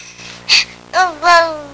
Baby Learning To Talk Sound Effect
Download a high-quality baby learning to talk sound effect.
baby-learning-to-talk.mp3